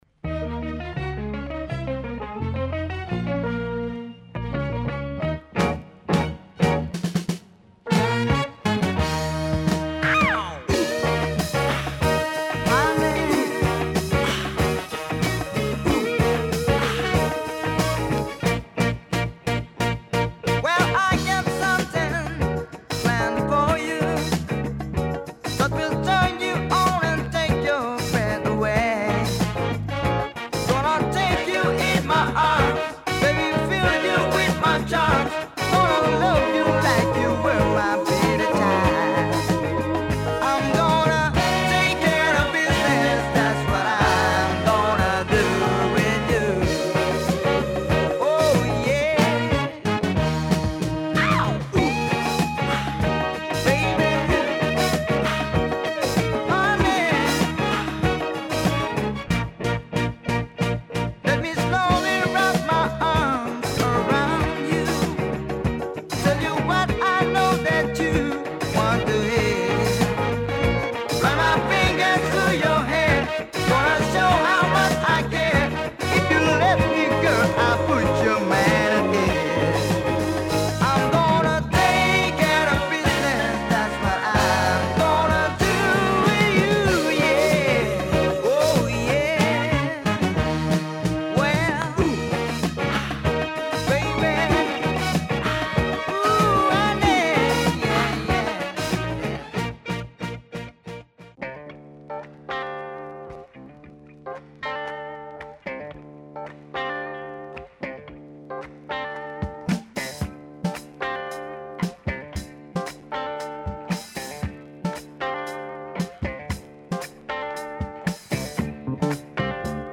Dope funk by this Philipino group. Really killer groove !